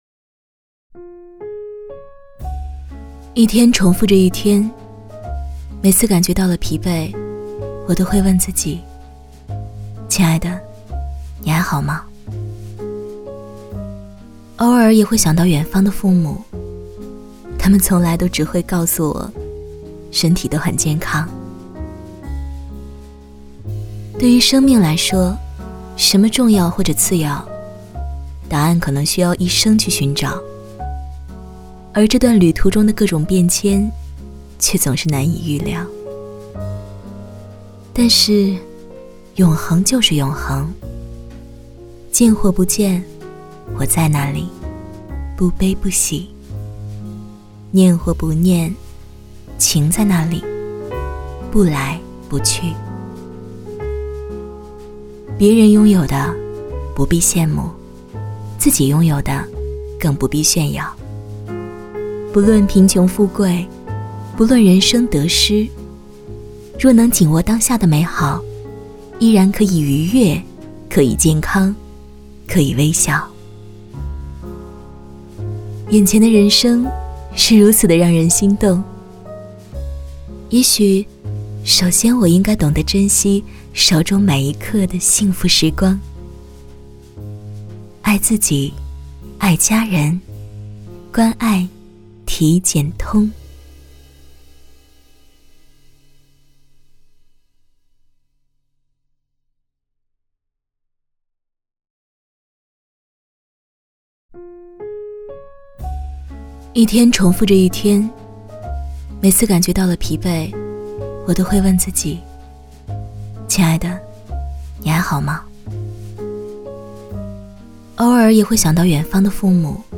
• 女S126 国语 女声 微电影旁白-体检通关爱自己【讲述温暖内心独白】〖甜度☆〗 低沉|积极向上|时尚活力|神秘性感|调性走心|亲切甜美|感人煽情|素人